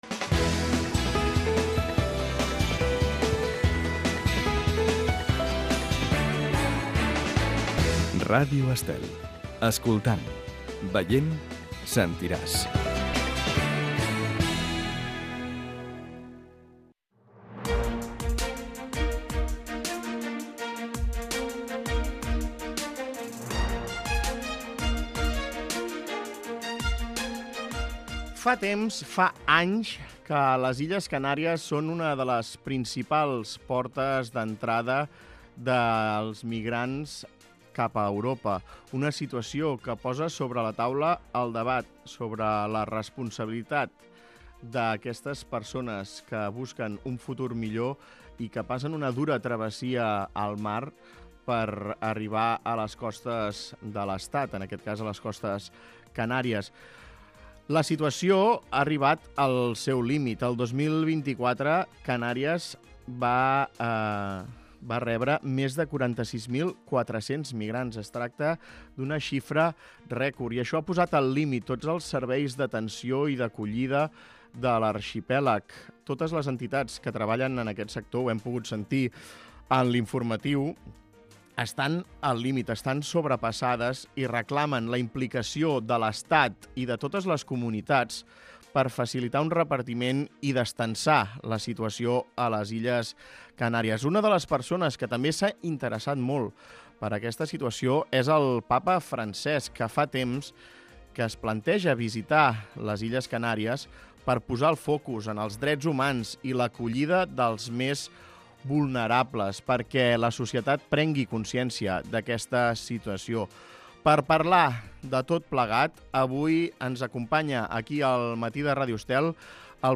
Escolta l'entrevista a Fernando Clavijo, president de Canàries